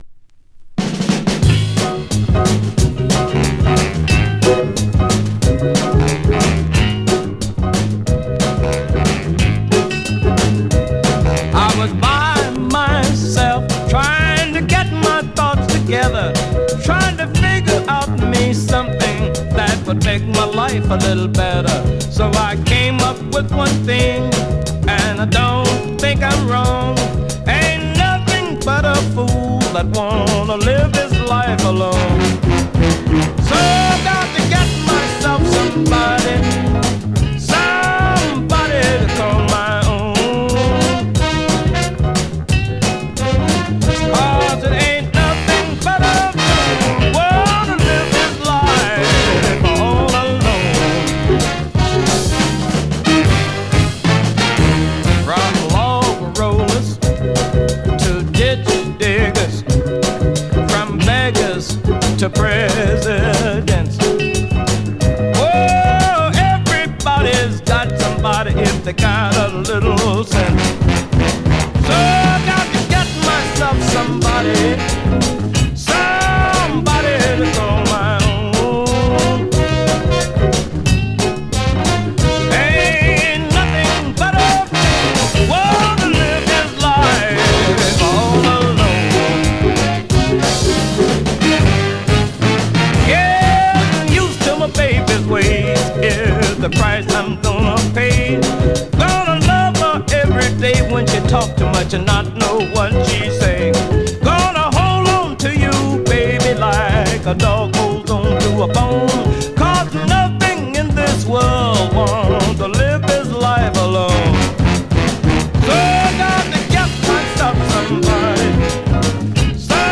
Great up-tempo dancer from this ever popular artist
R&B